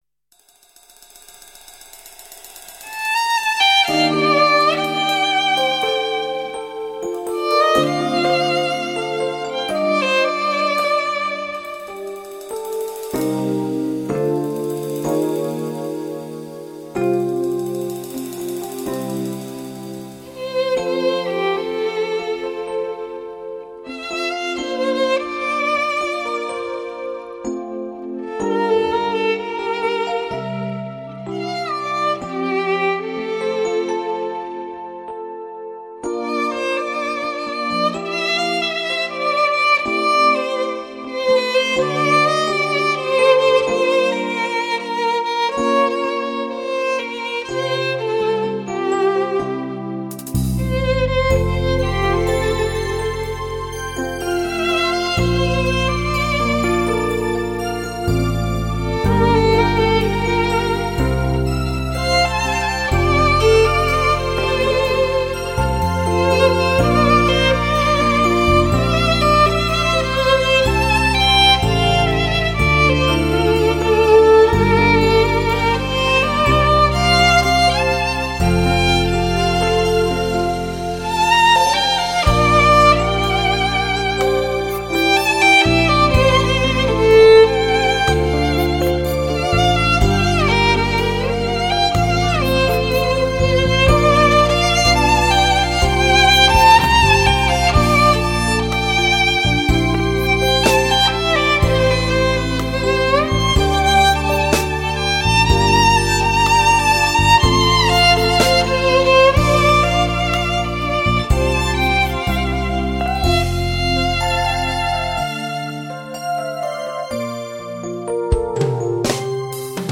全新数码录音特别版！
本碟用优雅的小提琴对流行音乐进行全新演绎。